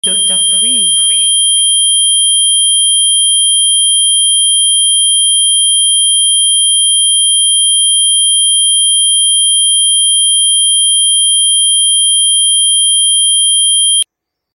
Régénérez vos cellules avec Royal Rife : 10 000 Hz + 3 200 Hz ⚡🧬🌀 Deux fréquences emblématiques de la méthode Rife pour soutenir le renouvellement cellulaire et favoriser une détente profonde.